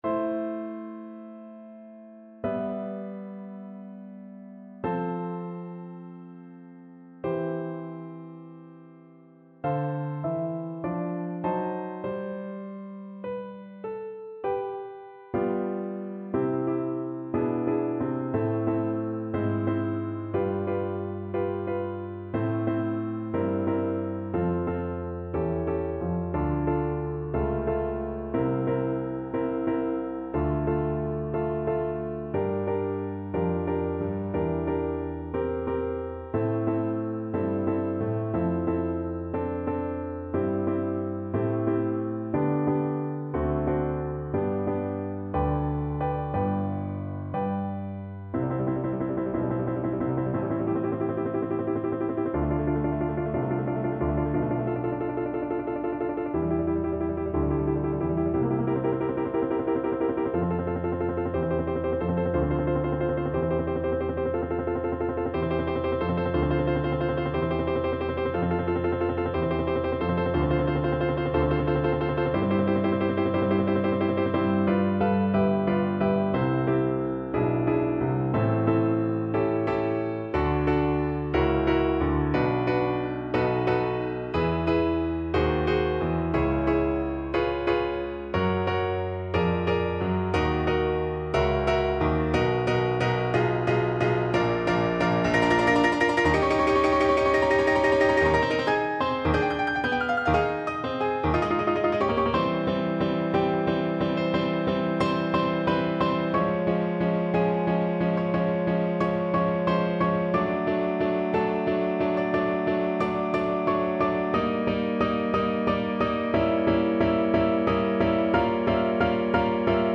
Moderato assai
4/4 (View more 4/4 Music)
Classical (View more Classical Flute Music)